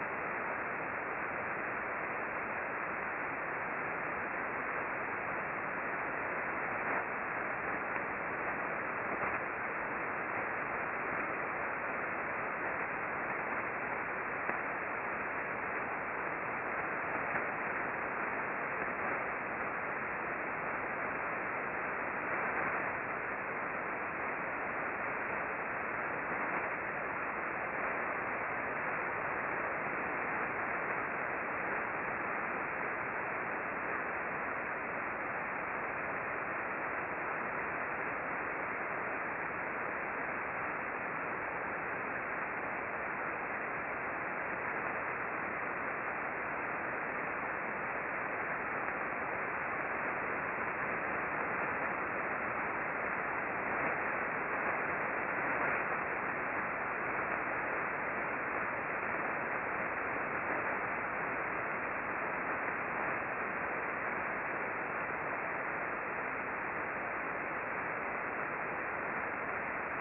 The ambient RF noise level in this direction is fairly high (over 300k K).
We observed mostly S-bursts that shifted from receiver to receiver during the bursting periods.